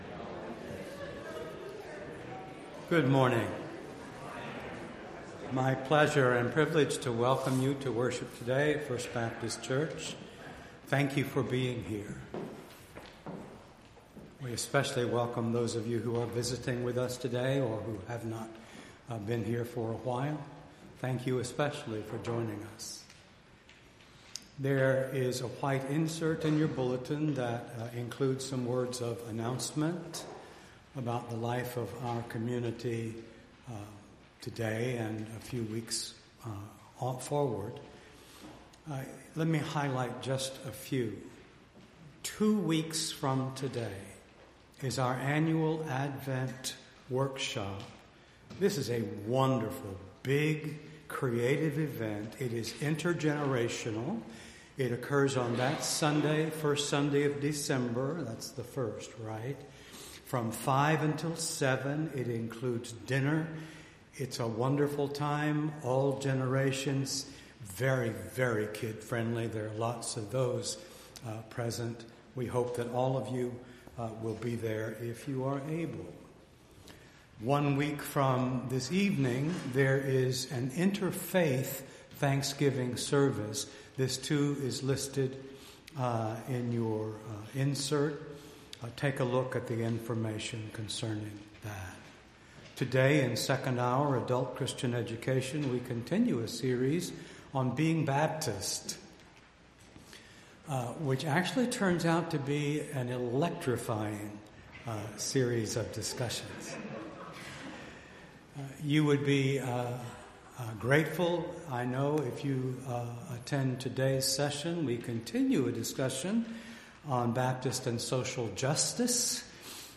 Entire November 17th Service